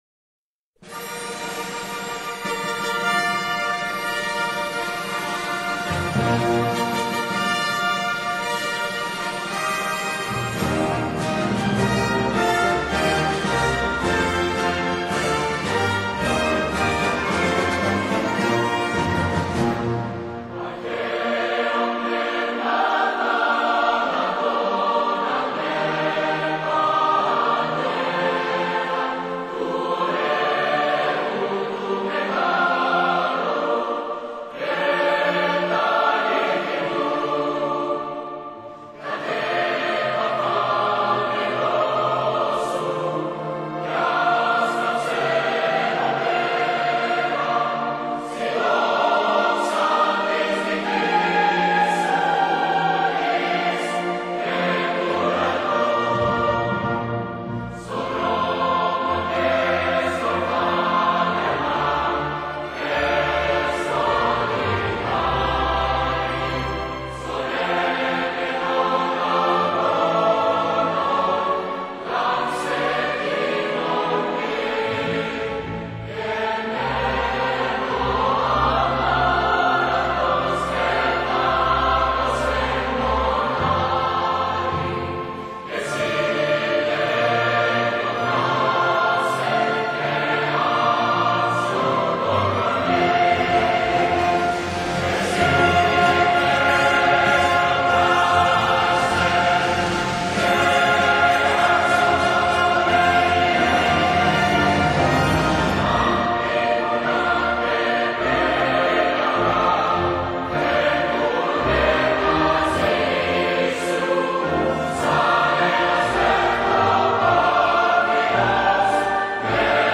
вокальная версия